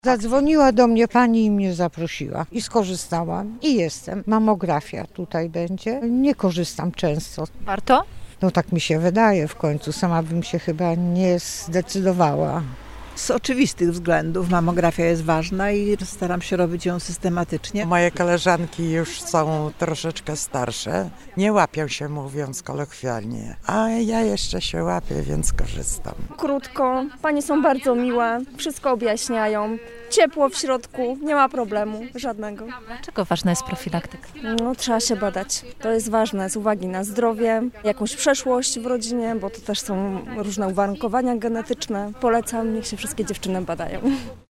01_sonda-badania-szpital.mp3